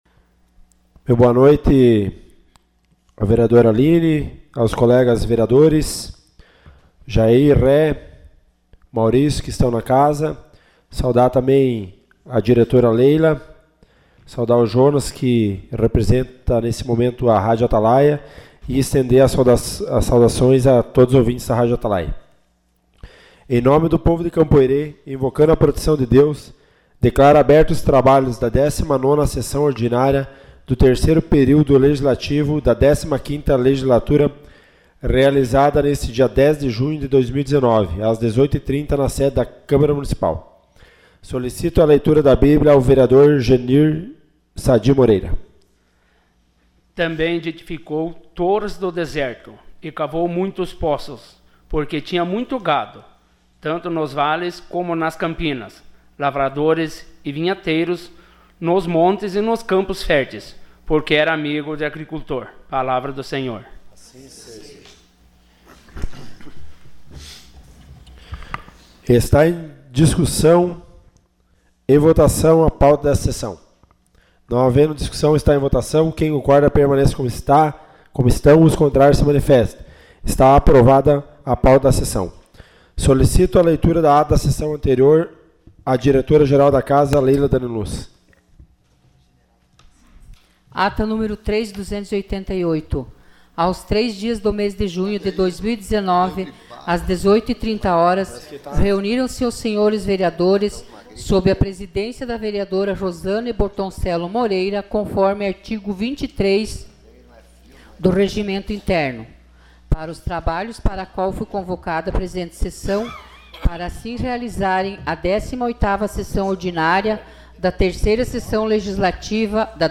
Sessão Ordinária 10 de junho de 2019.